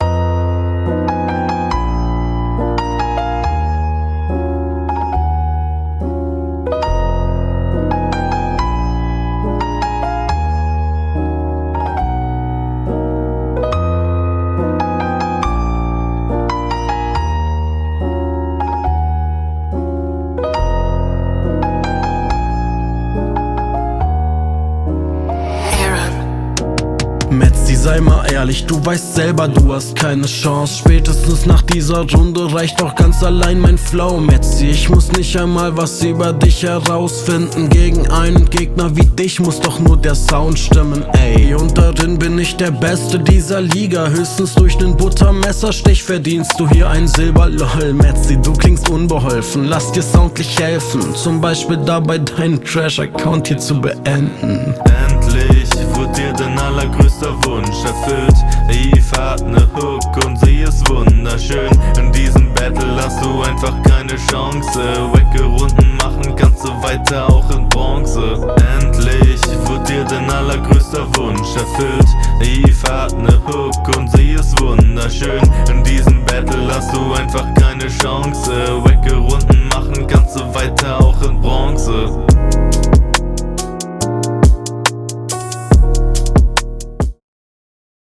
die hook klingt als wär sie in 3 falschen keys auf einmal